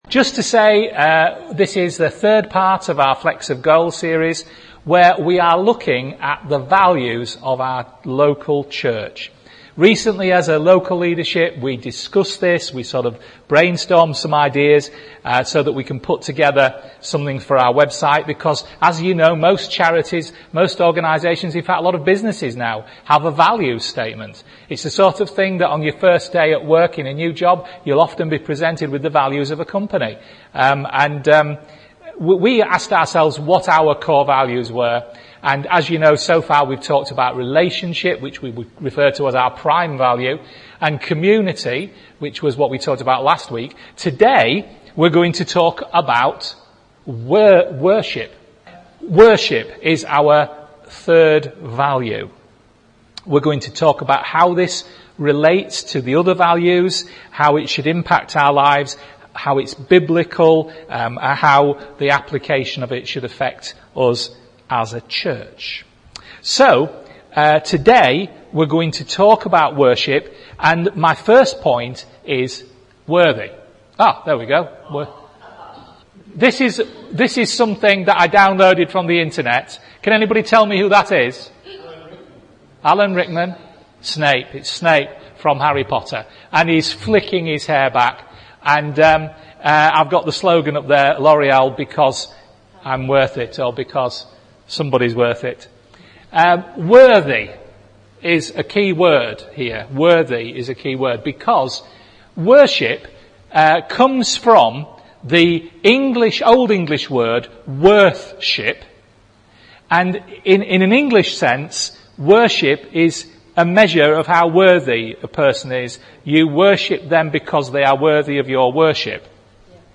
A preaching series that examines our core values as a church, ask ourselves why we hold them so precious, talk about their biblical basis and also what application we should see in our lives and in this church as we implement them. Today we discuss 'worship'